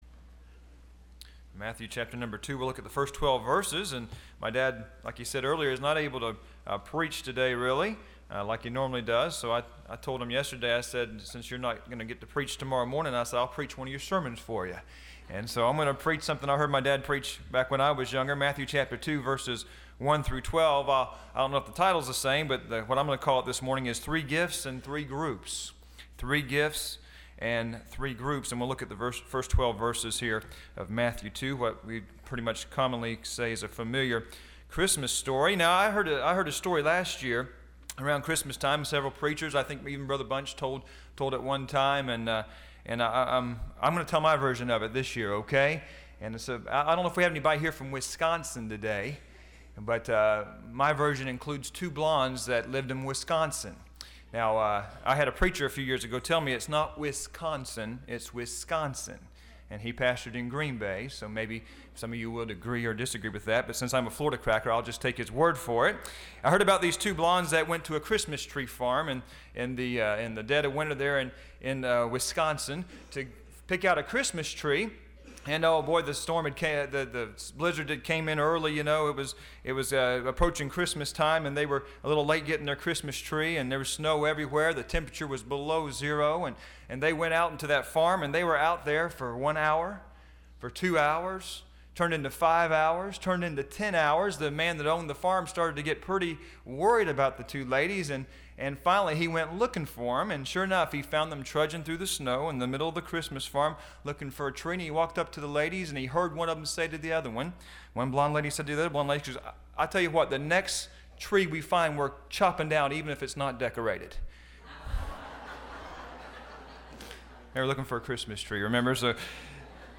Listen to Message
Service Type: Sunday Morning